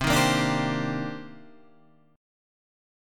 C Minor 9th